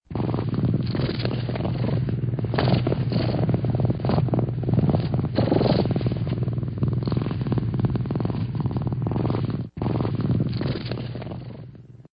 Djur, Musik, Katter